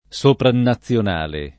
vai all'elenco alfabetico delle voci ingrandisci il carattere 100% rimpicciolisci il carattere stampa invia tramite posta elettronica codividi su Facebook soprannazionale [ S opranna ZZL on # le ] (meglio che sopranazionale ) agg.